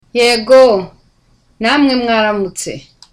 (Smiling too.)